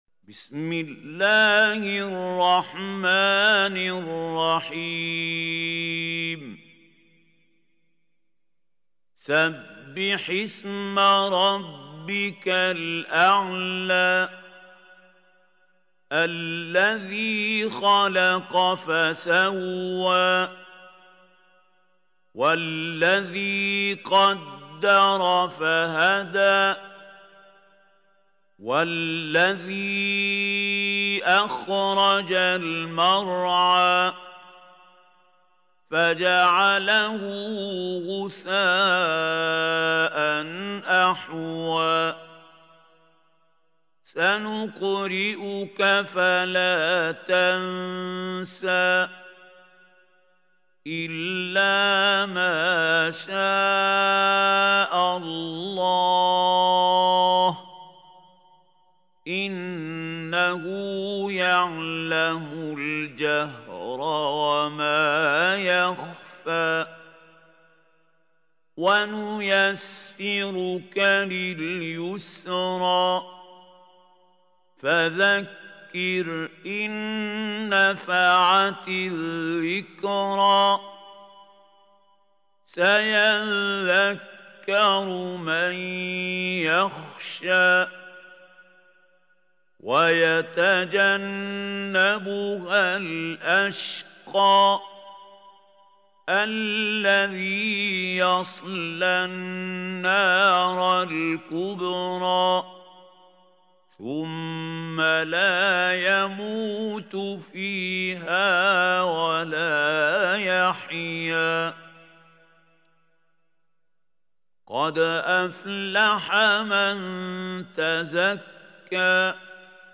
سورة الأعلى | القارئ محمود خليل الحصري